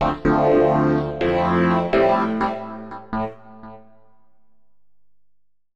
SYNTH026_VOCAL_125_A_SC3(R).wav